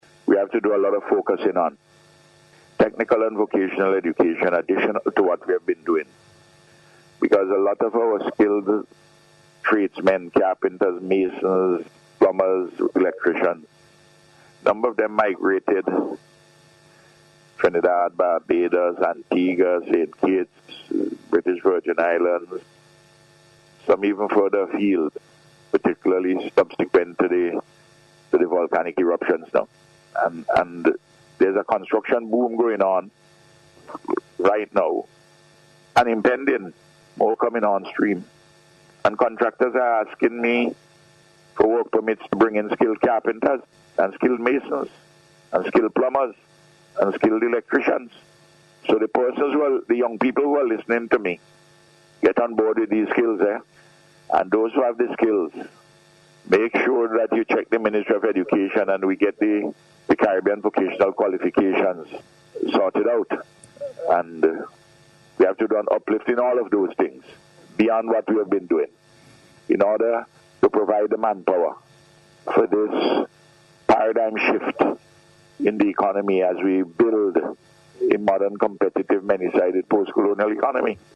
Speaking on Radio this morning, the Prime Minister who now has Ministerial responsibility for Post-Secondary Education, said the public can look forward to further advances in this area.